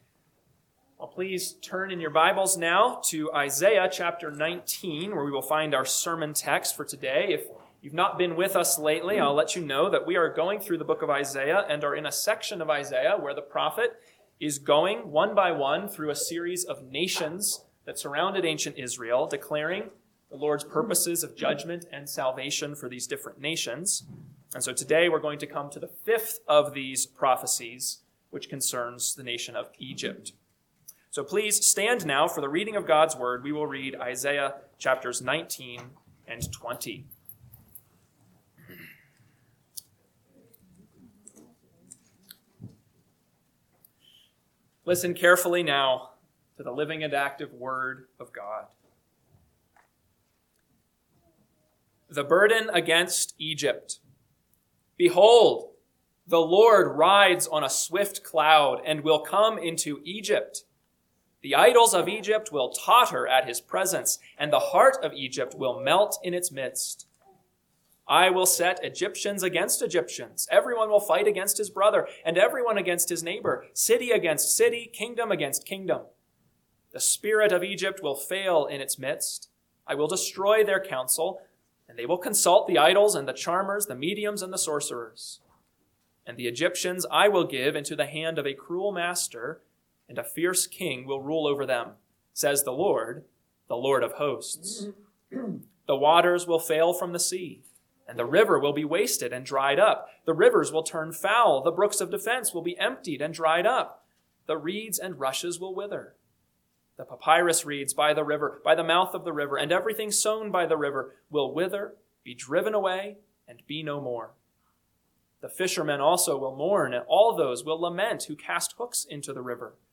AM Sermon – 3/8/2026 – Isaiah 19-20 – Northwoods Sermons